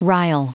Prononciation du mot rile en anglais (fichier audio)
Prononciation du mot : rile